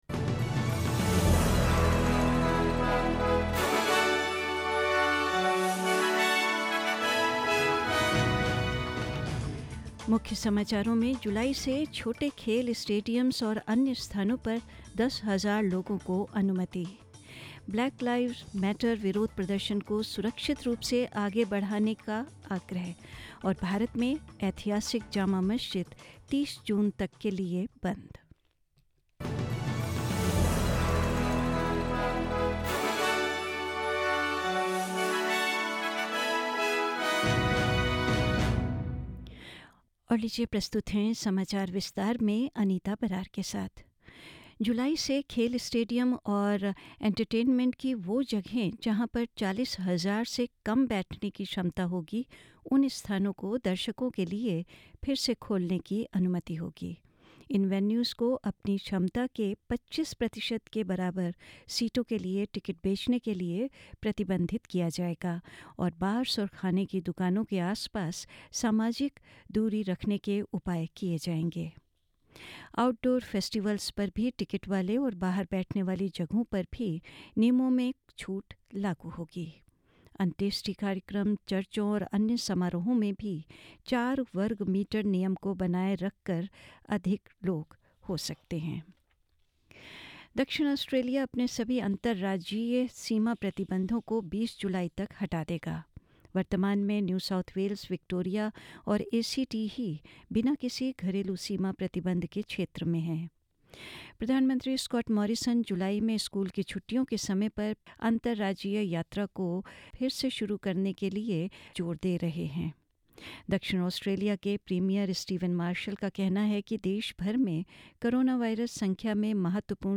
In this bulletin...** Up to 10,000 people allowed in smaller sports stadiums and other venues from July...** Governments and police urged to allow Black Lives Matter protests to proceed safely...